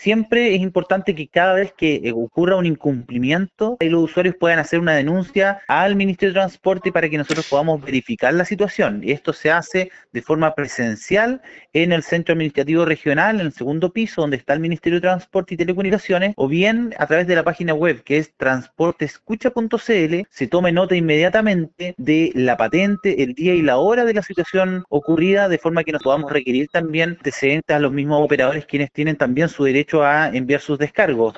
Ante ello, el seremi Joost hizo un llamado a la ciudadanía a denunciar estos hechos.